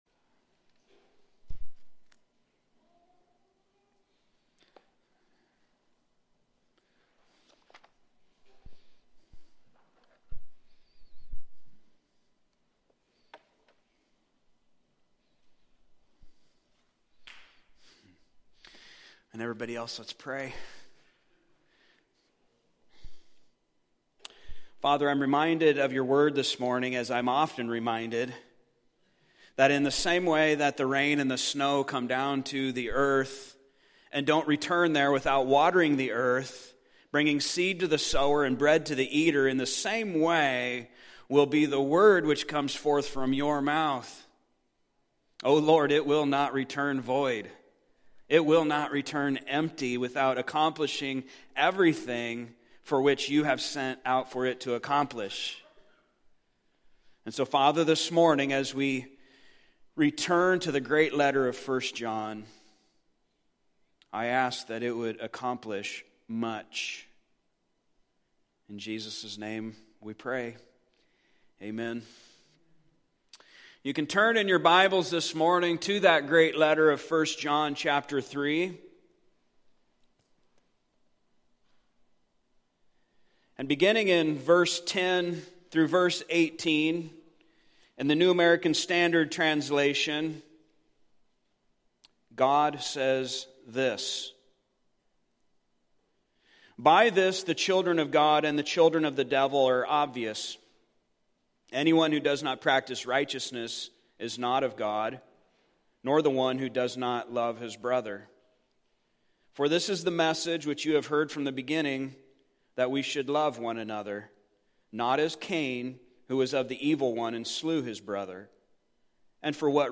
Service Type: Sunday Morning Topics: Christian Life , Love , Racism